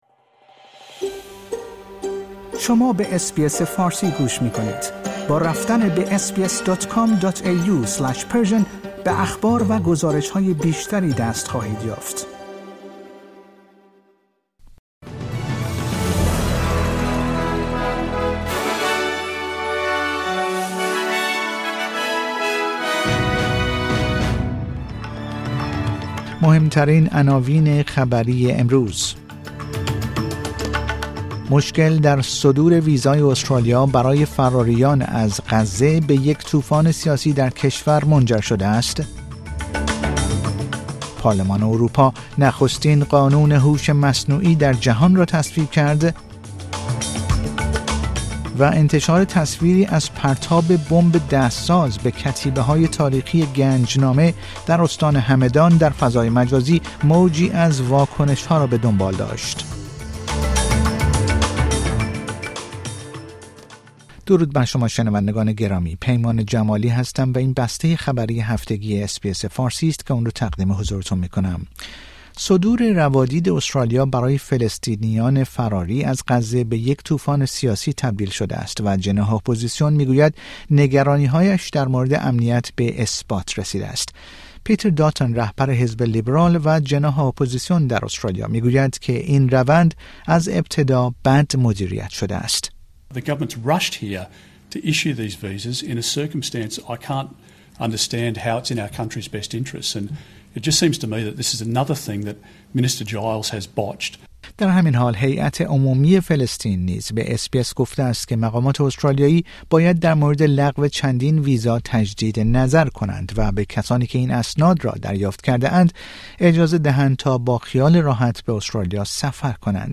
در این پادکست خبری مهمترین اخبار استرالیا، جهان و ایران در یک هفته منتهی به شنبه ۱۶ مارچ ۲۰۲۴ ارائه شده است.